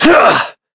Shakmeh-Vox_Attack1.wav